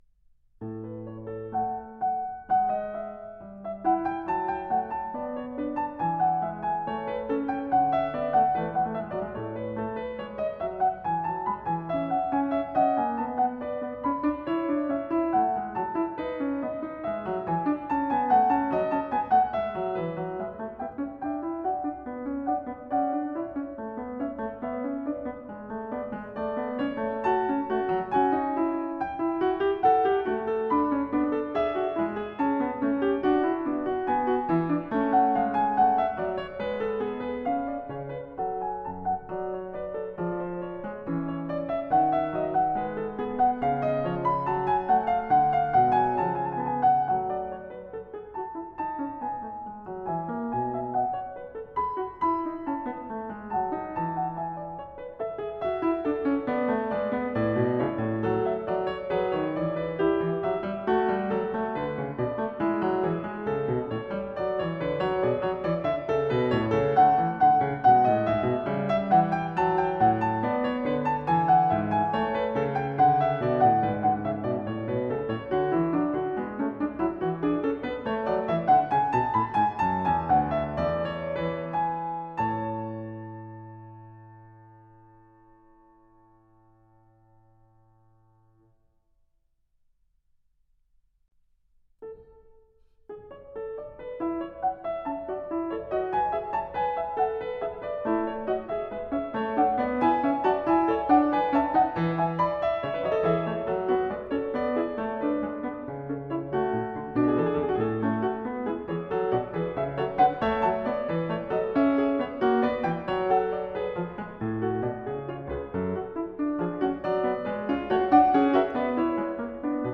Piano  (View more Advanced Piano Music)
Classical (View more Classical Piano Music)
Audio: Kimiko Ishizaka